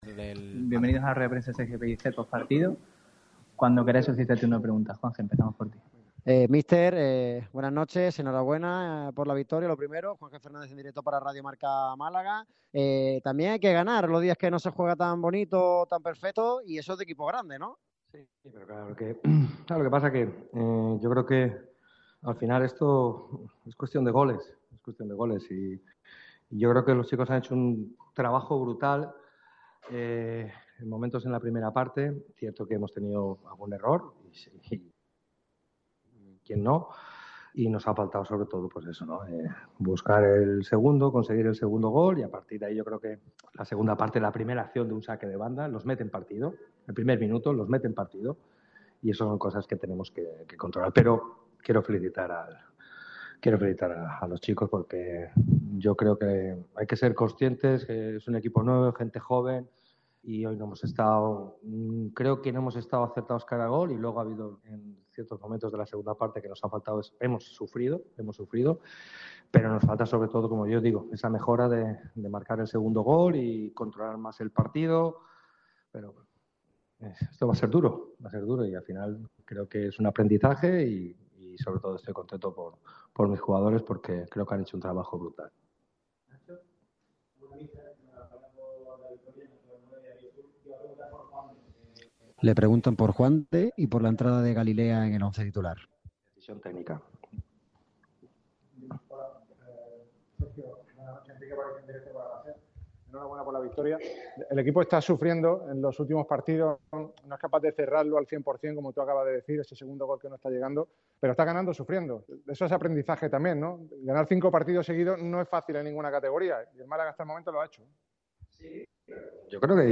El técnico de Nules ha comparecido ante los medios de comunicación tras la victoria malaguista sobre el San Fernando 1-0 en La Rosaleda.